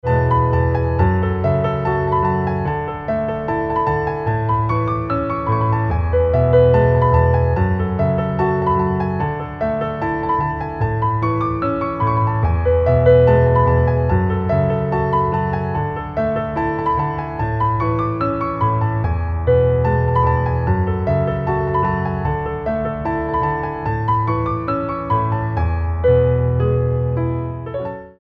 • Качество: 320, Stereo
без слов
инструментальные
пианино
романтичные
рояль